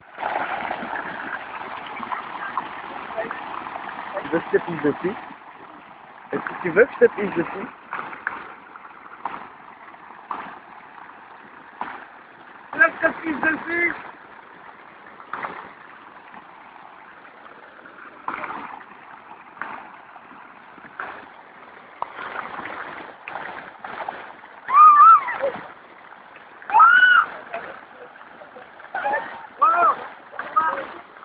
fontaine, personnes qui parlent